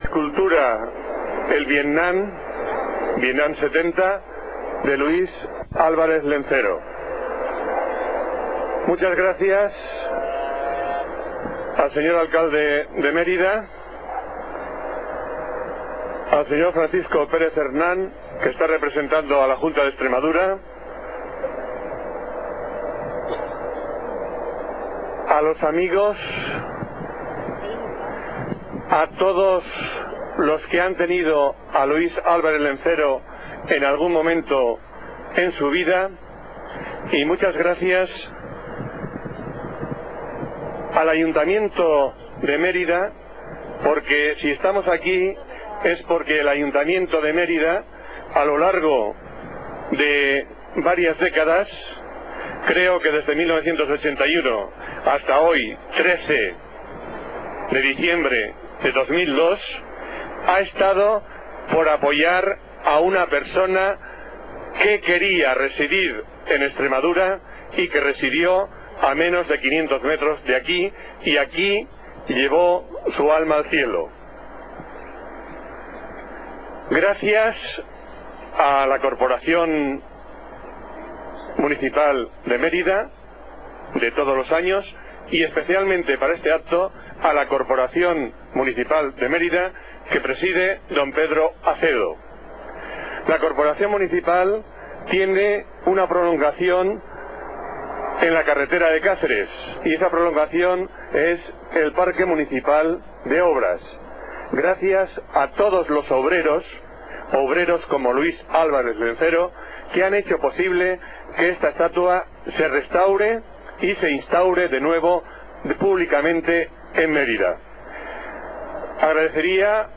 Inauguración de la estatua Vietnam 70
(Glorieta de la Avenida de los Rosales, cruce N-V y carretera de Alange, en la ciudad de Mérida).
acompañadas por la lluvia